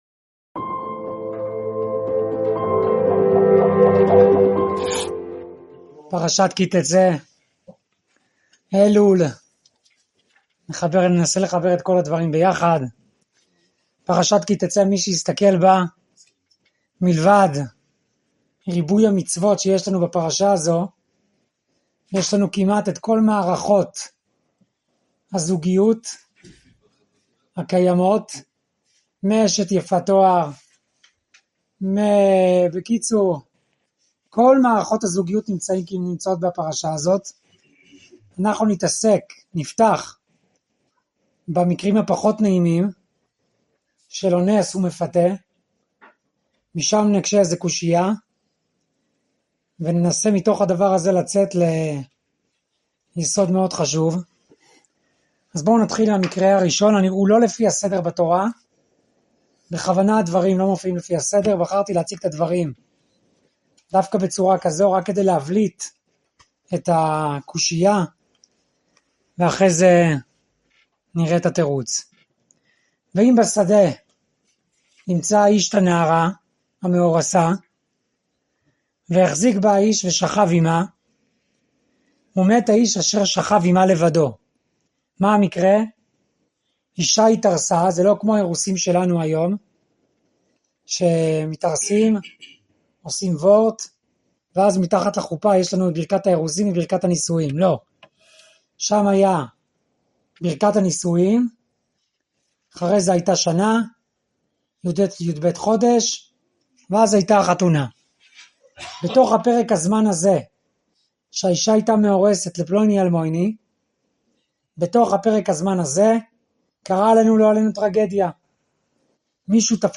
שיעור 35 מתוך 35 בסדרת ויורנו מדרכיו - תשפ"ה